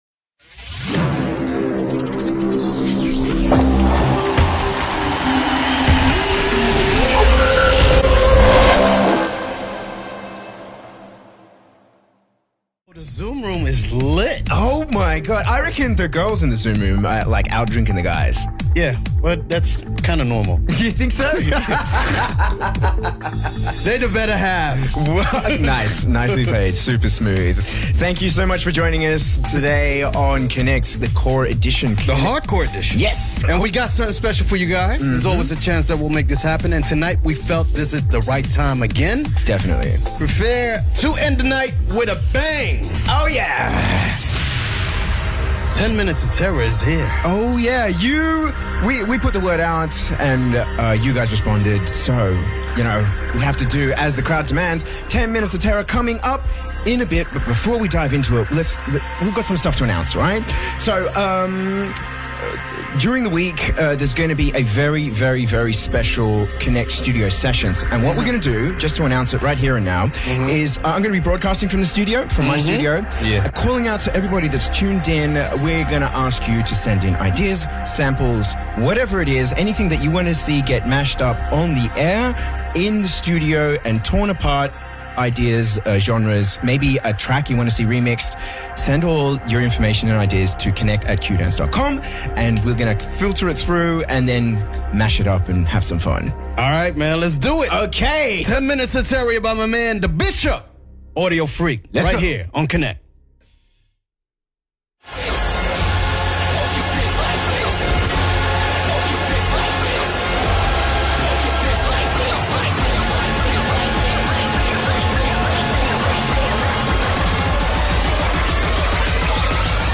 Genre: Hardcore , Hardstyle